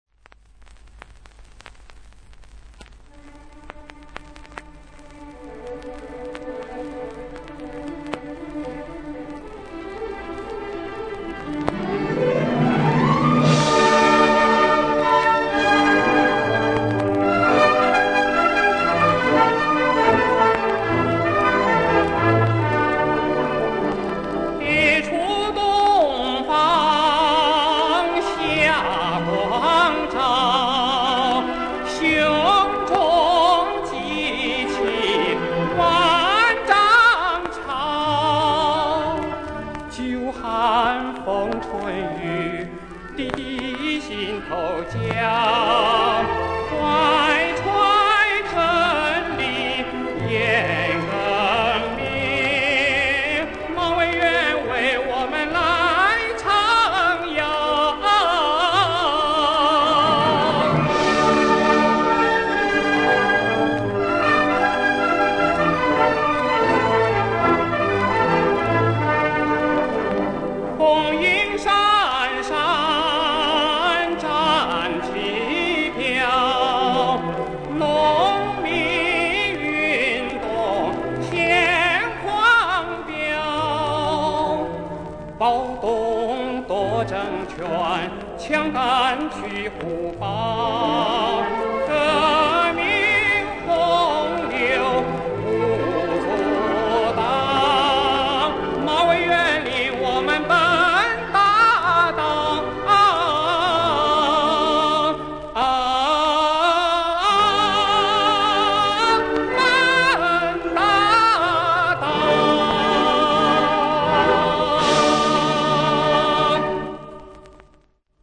插曲